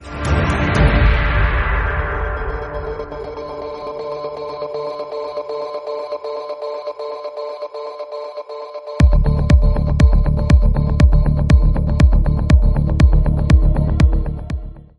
Как сделать такой вокальный эффект
В одном из референсов услышал такой классный вокальный эффект который вырезал из референса. После начала вступает как бы мужской вокальный FX скажем так.